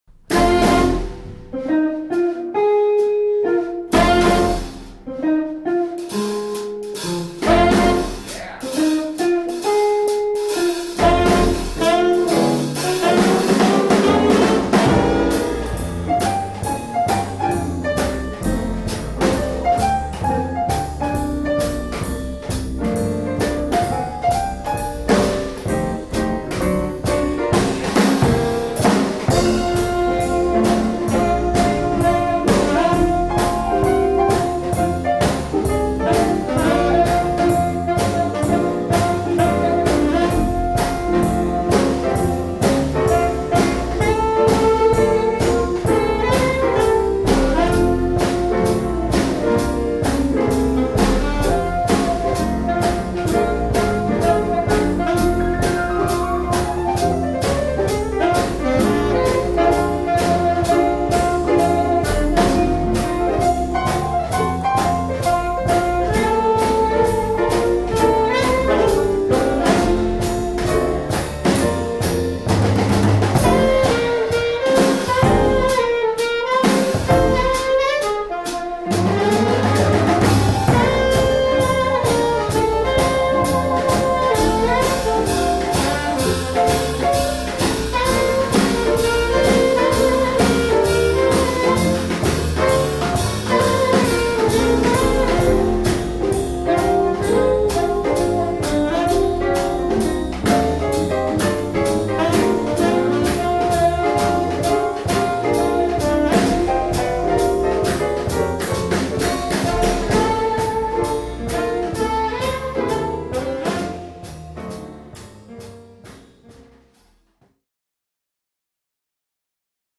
• Jazzbands